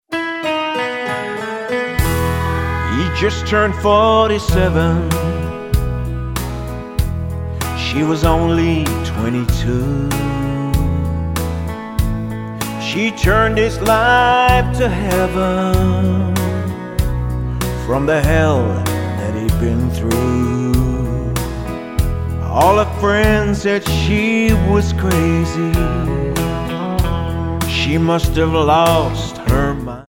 Tonart:E-F Multifile (kein Sofortdownload.
Die besten Playbacks Instrumentals und Karaoke Versionen .